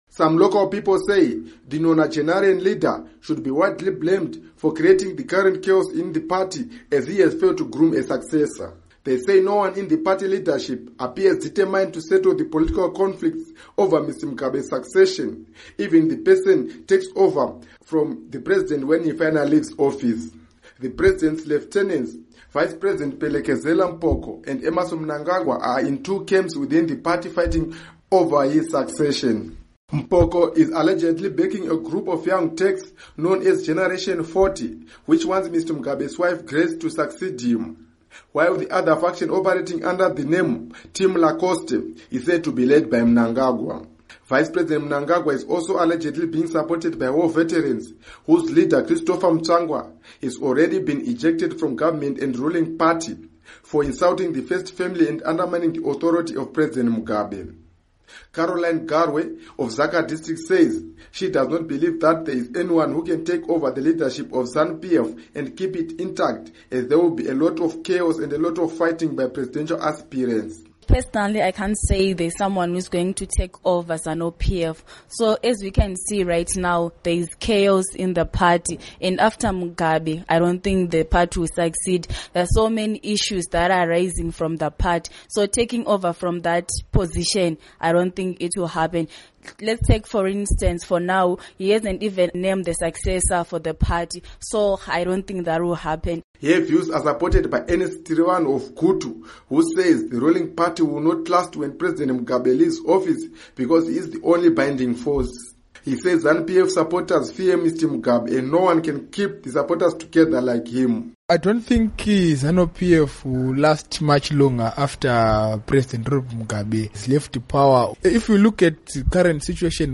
Report on Mugabe Succession